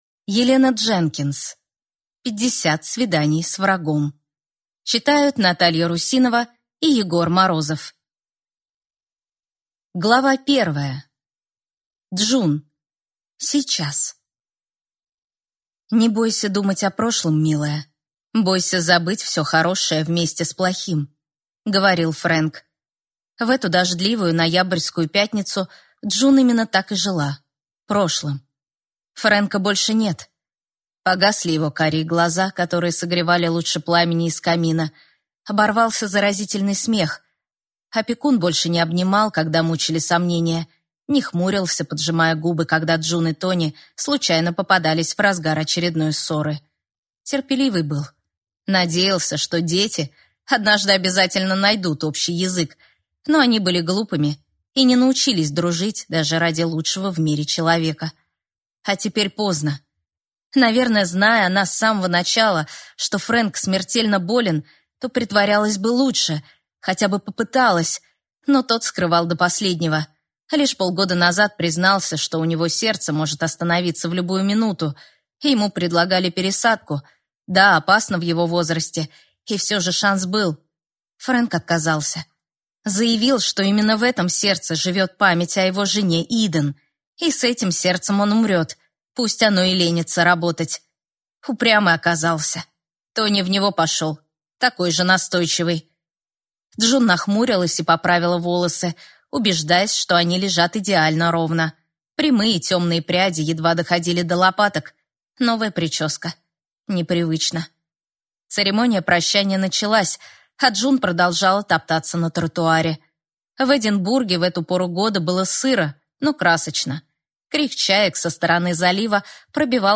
Аудиокнига Пятьдесят свиданий с врагом | Библиотека аудиокниг